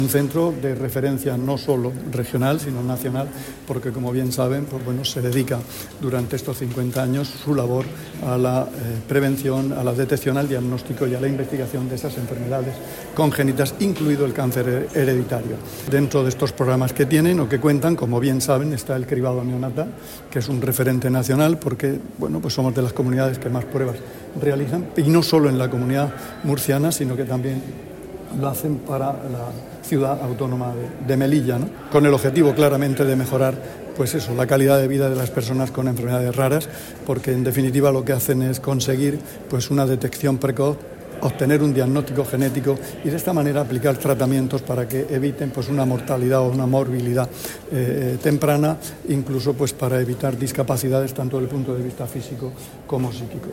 Declaraciones del consejero de Salud, Juan José Pedreño, sobre el trabajo que realiza el Centro regional de Bioquímica y Genética Clínica.
El consejero de Salud, Juan José Pedreño, en la inauguración de los actos del 50 aniversario del Centro regional de Bioquímica y Genética Clínica.